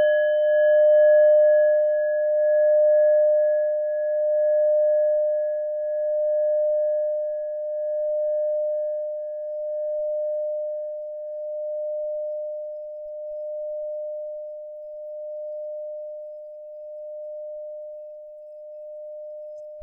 Nepal Klangschale Nr.9
Den Hauptbestandteil aller Legierungen bildet immer Kupfer.
Hörprobe der Klangschale
(Ermittelt mit dem Filzklöppel)
klangschale-nepal-9.wav